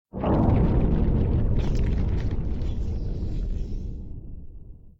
WallDecay2.ogg